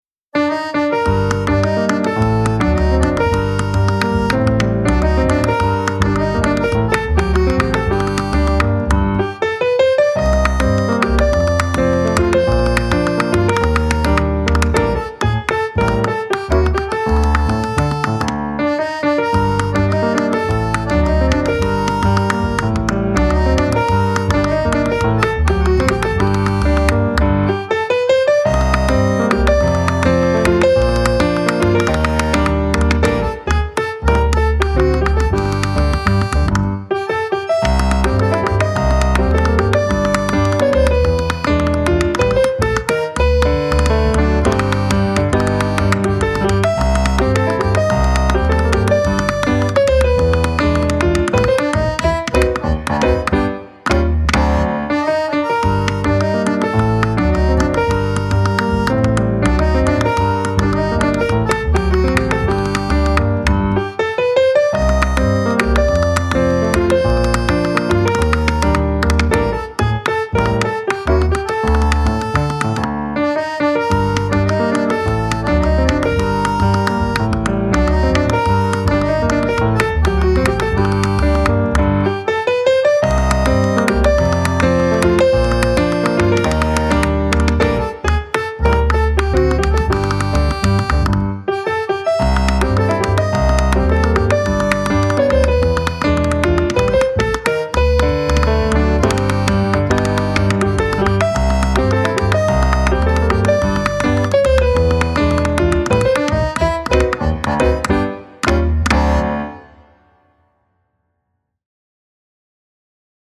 World
musique pour documentaire